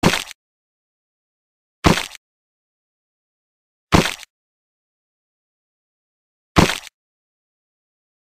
Sonneries » Sons - Effets Sonores » bruit d’os brisés